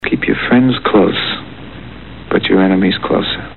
(8) Which Al Pacino line ISN'T as the legendary character Michael Corleone?